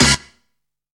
SCREECHIN.wav